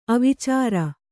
♪ avicāra